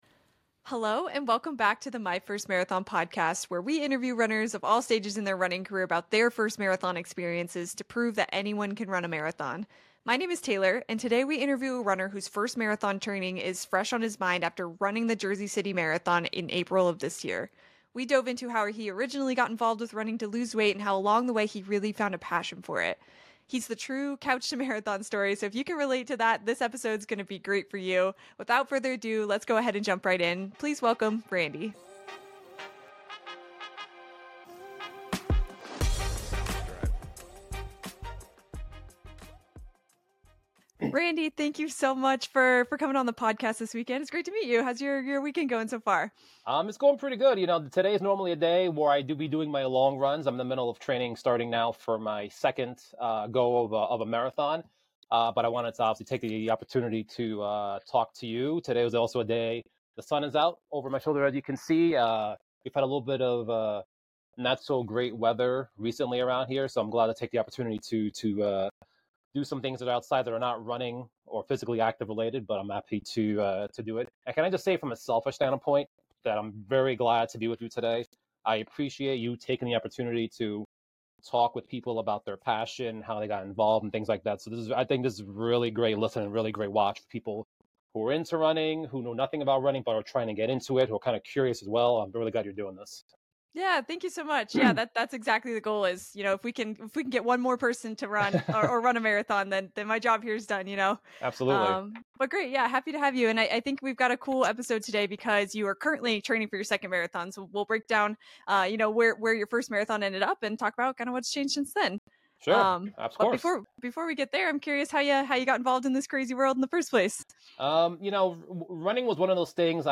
On today's episode we interview a runner whose first marathon training is fresh on his mind after running the Jersey City Marathon in April of this year. We dove into how he originally got involved with running to lose weight and how along the way he really found a passion for...